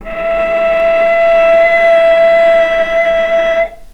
vc-E5-mf.AIF